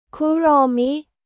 คุโรมิ　ク・ロー・ミ